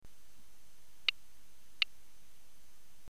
Er liegt nahe am Kammerton a (440Hz).
Und hier ein Klangbeispiel mit 2 tiefen Signaltönen, bei dem die Tonlänge sehr kurz konfiguriert ist:
mini-meditationsuhr-ton-kurz.mp3